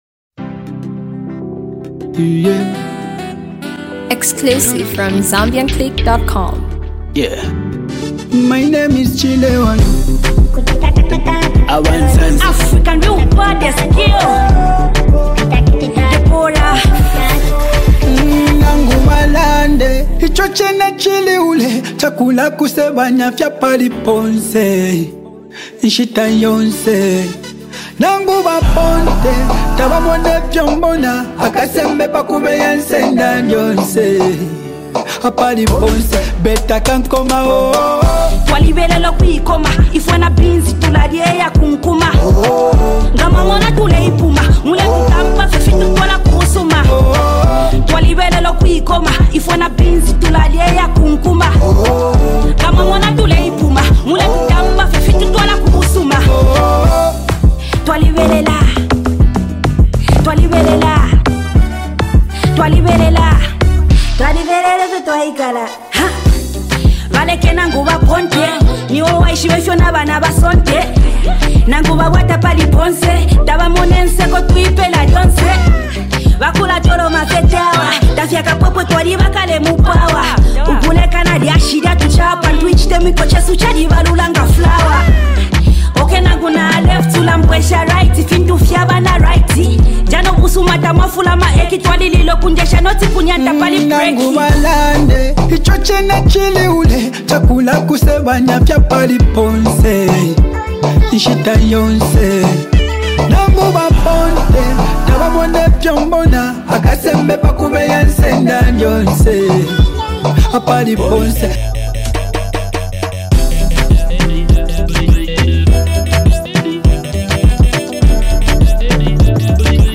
country groove